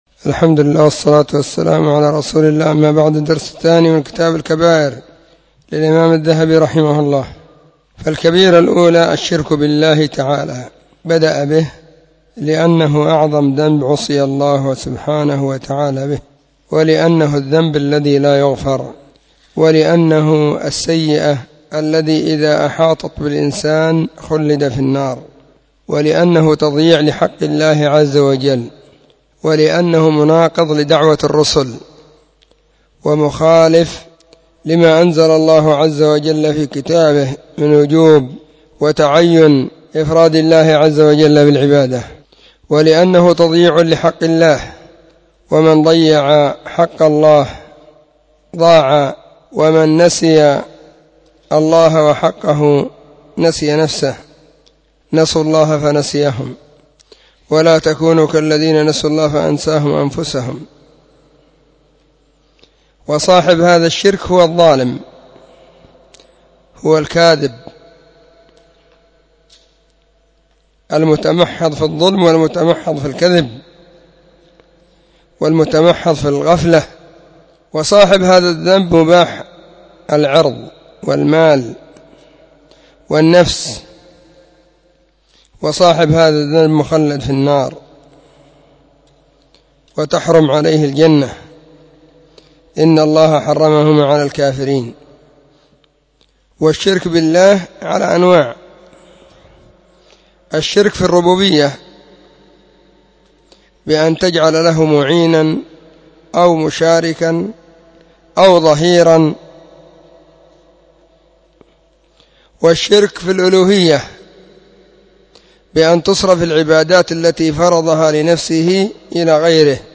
📢 مسجد الصحابة – بالغيضة – المهرة، اليمن حرسها الله.…
🕐 [بين مغرب وعشاء – الدرس الثاني]